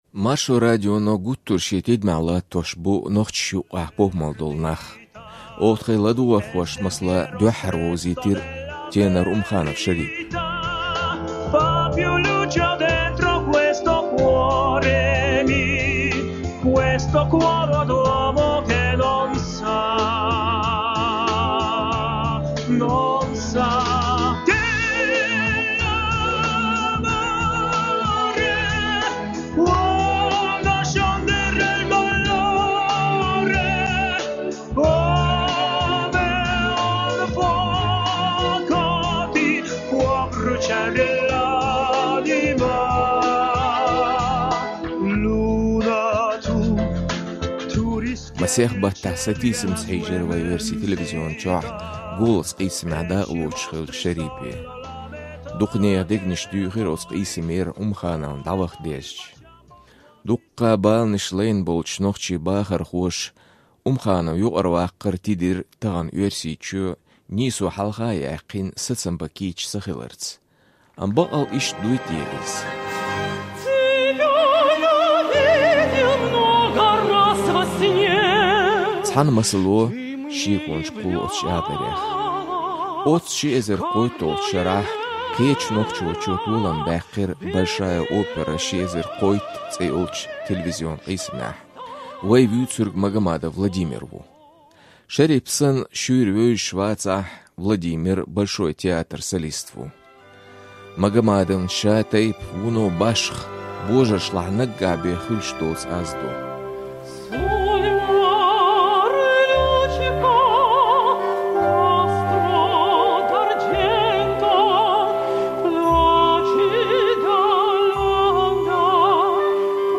Контратенор